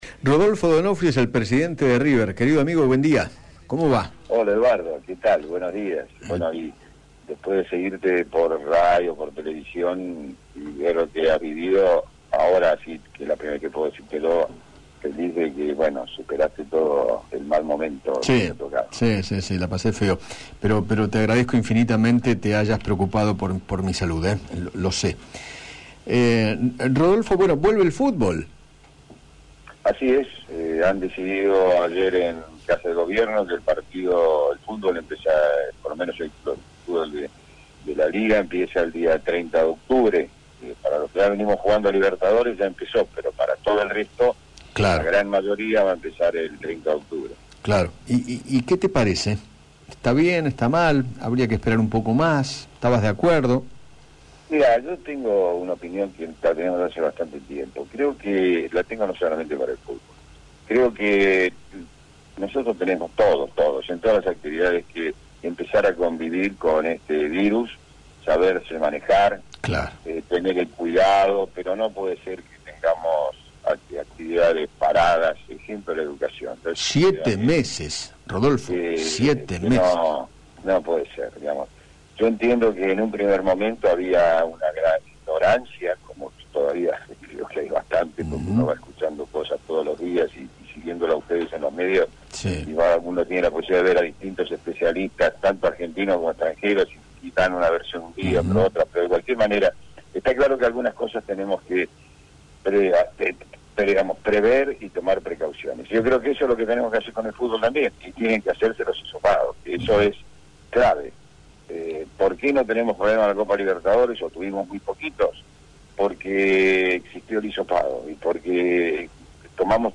Rodolfo D’Onofrio, Presidente de River Plate, conversó con Eduardo Feinmann acerca del regreso del fútbol argentino, que comenzará el 30 de octubre, y dio su punto de vista al respecto.